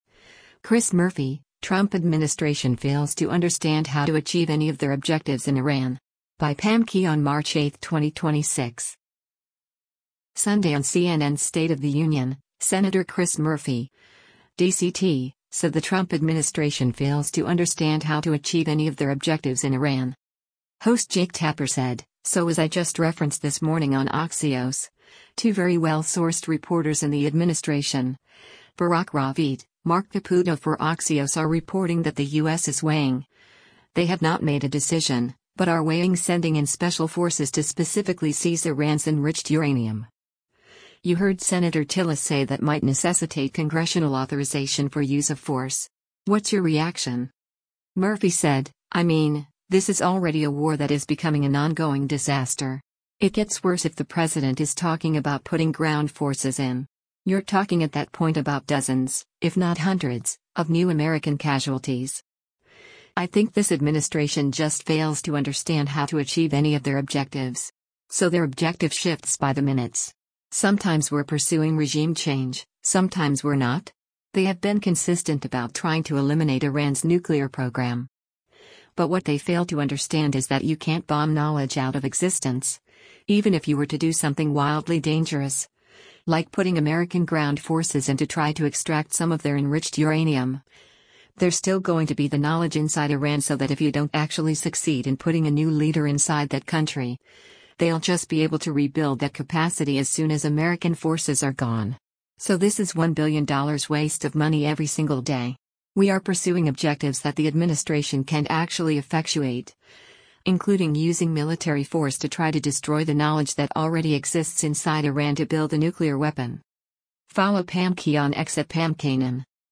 Sunday on CNN’s “State of the Union,” Sen. Chris Murphy (D-CT) said the Trump administration “fails to understand how to achieve any of their objectives” in Iran.